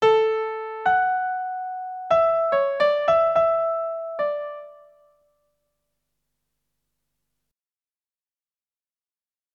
001 - La 440 (La 3)